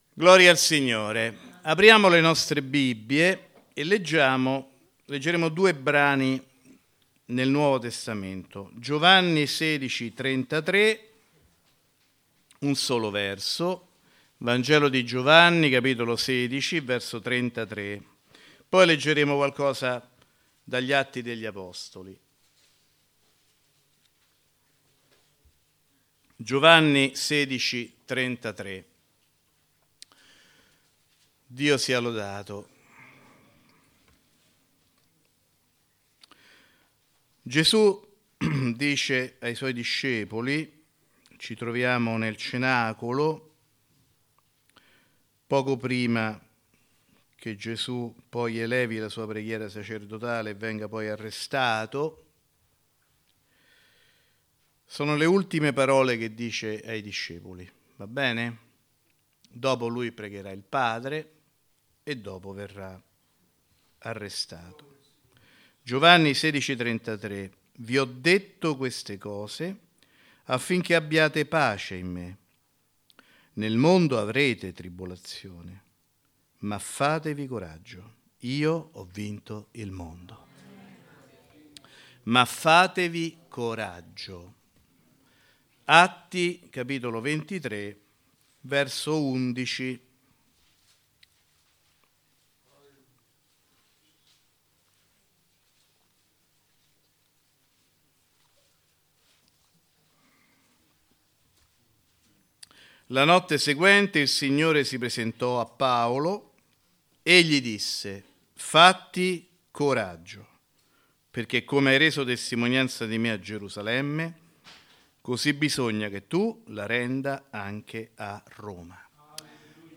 Predicatore